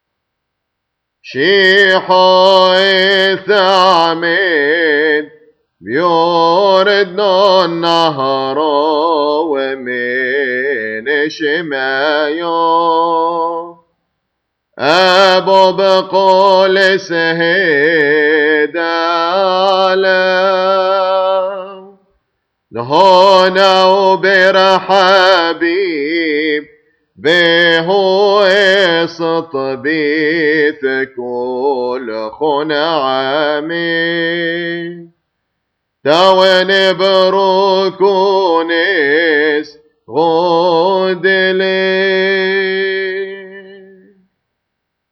Feast of the Epiphany Hymns
Concluding Hymn